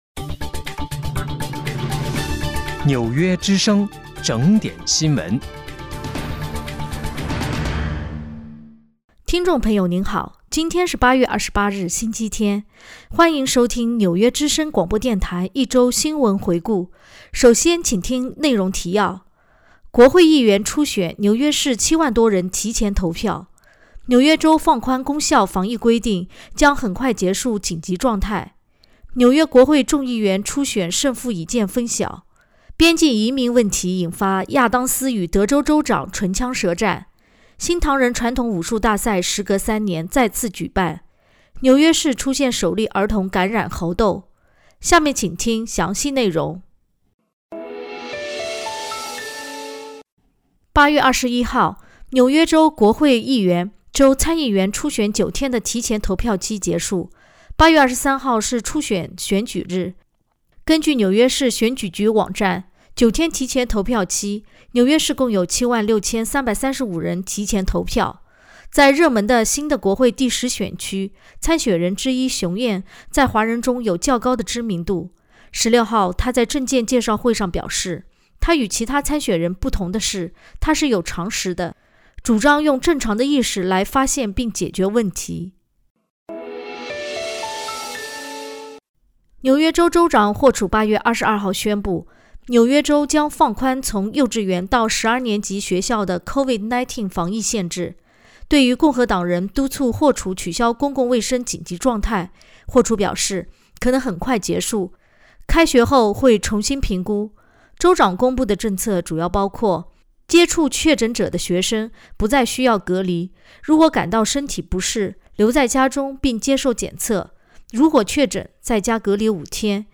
8月28日（星期日）一周新闻回顾